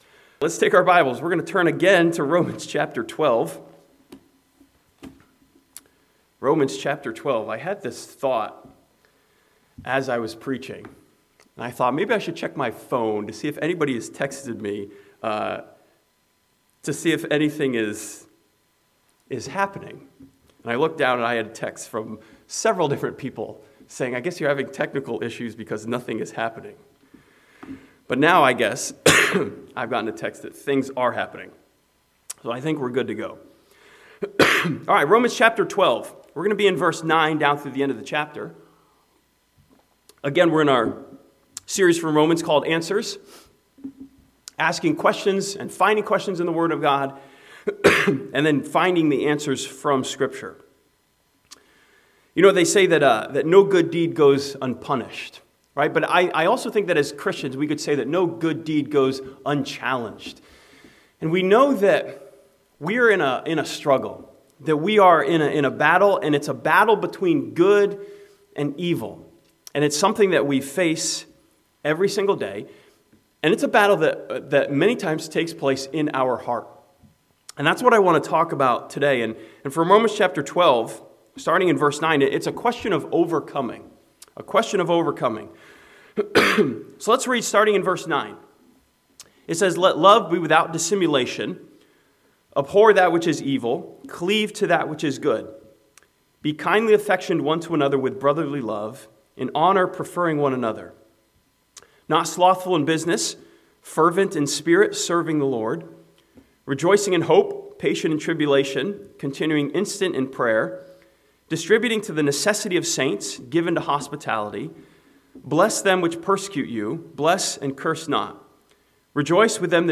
This sermon from Romans chapter 12 challenges us to overcome the evil of insincerity with the good of brotherly love.